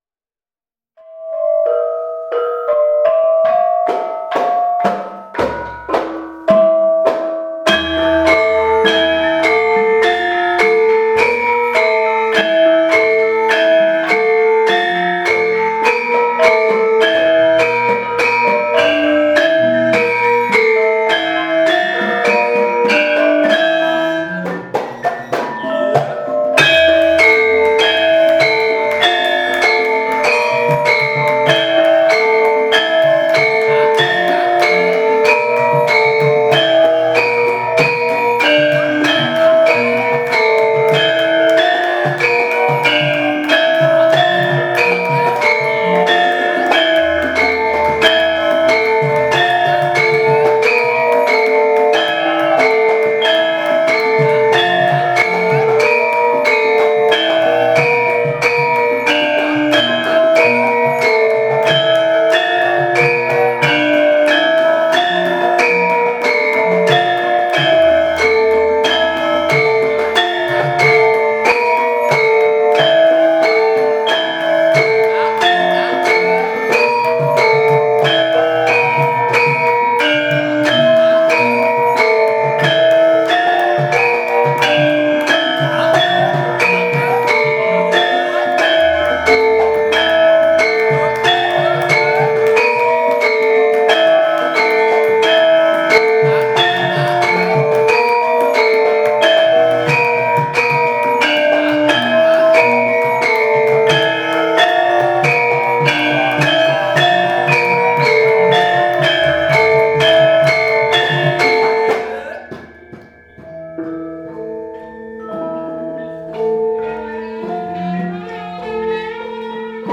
Kendhang
guest rebab player
while the group is rehearsing.